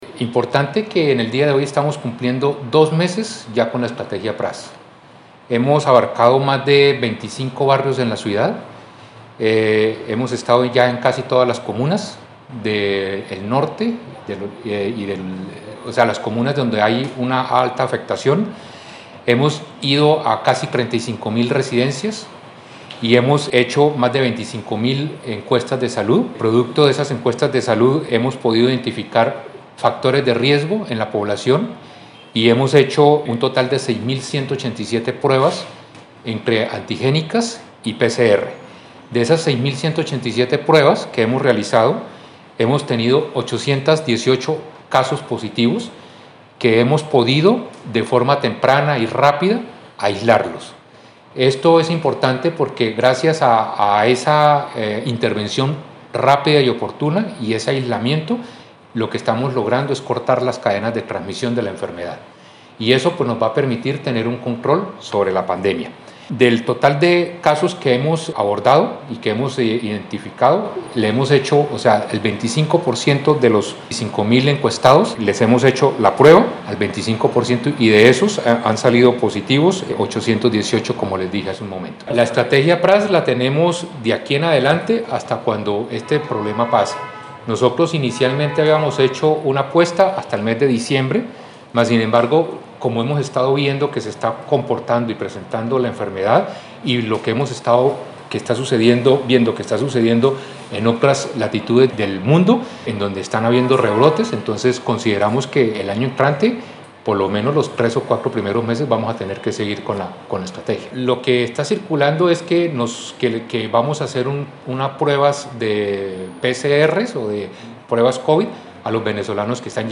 Nelson-Ballesteros-secretario-de-Salud-de-Bucaramanga-1.mp3